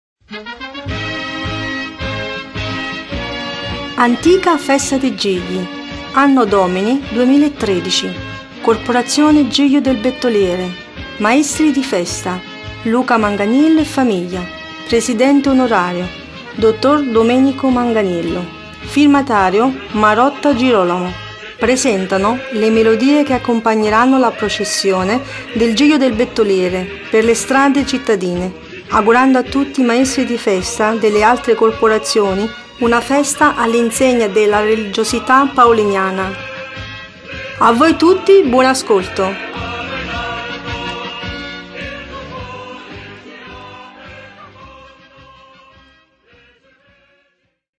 Presentazione